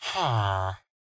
mob / villager / idle2.ogg